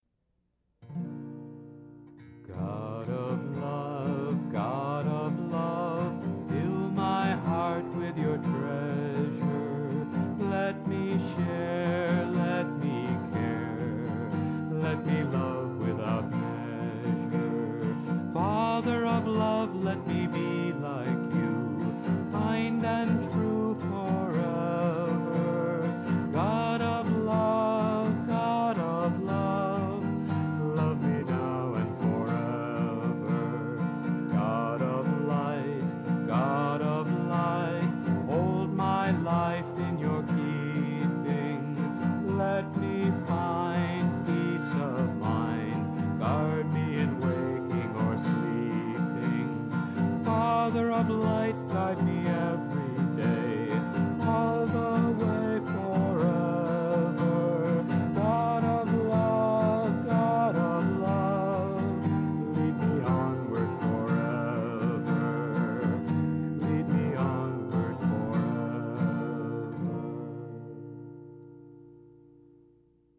1. Devotional Songs
8 Beat / Keherwa / Adi
Medium Slow